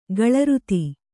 ♪ gaḷa ruti